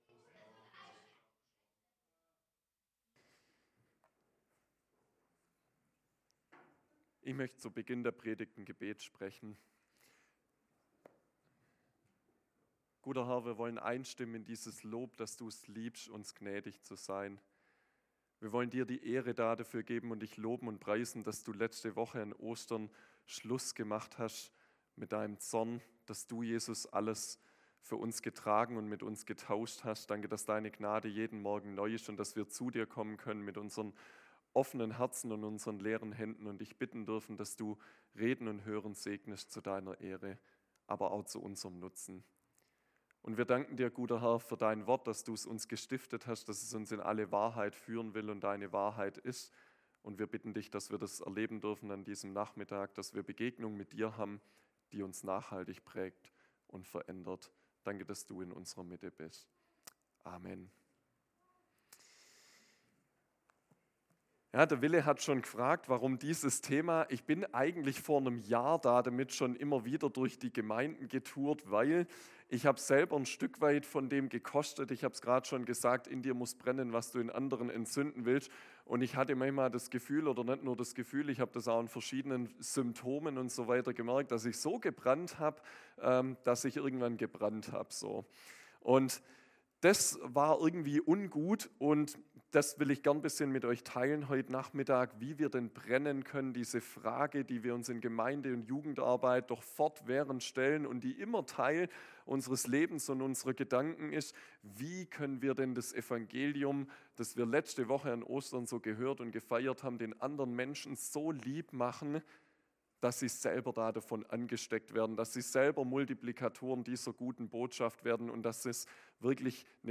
Gottesdienst am 27.04.2025